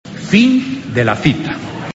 Los sonidos del maquinillo